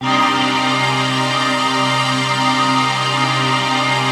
HOUSPAD12.wav